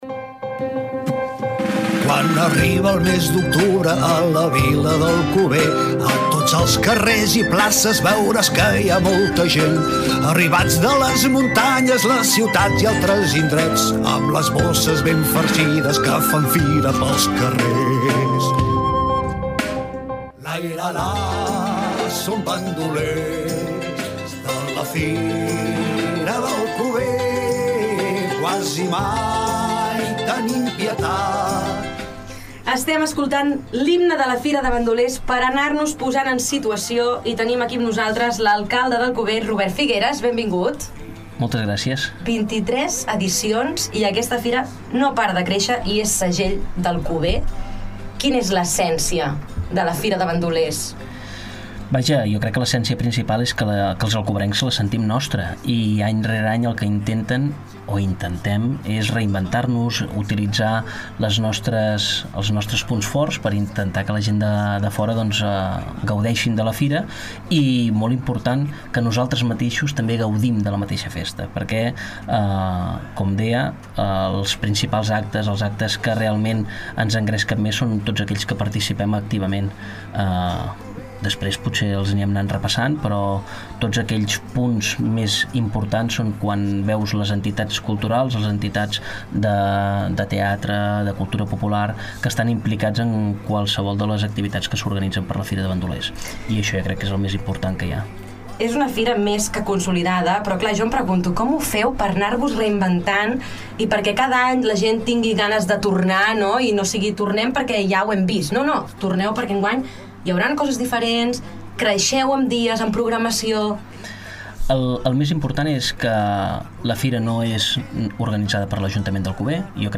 Nova entrevista a Ràdio Ciutat de Valls. Conversa amb l’alcalde d’Alcover, Robert Figueras, per conèixer tots els detalls de la Fira de Bandolers que tindrà lloc del 10 al 12 d’octubre.